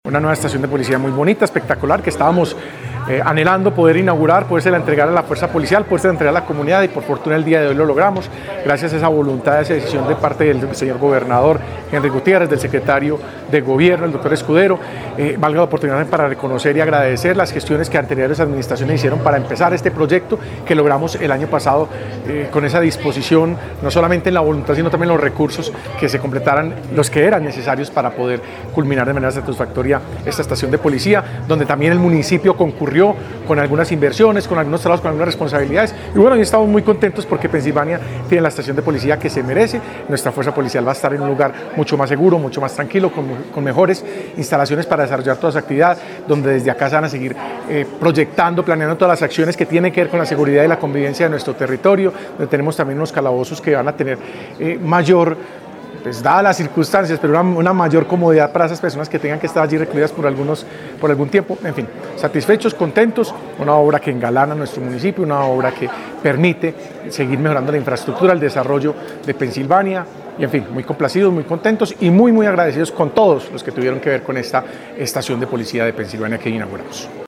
Jesús Iván Ospina Atehortúa, alcalde del municipio de Pensilvania